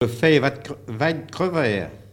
Notre-Dame-de-Monts
Locutions vernaculaires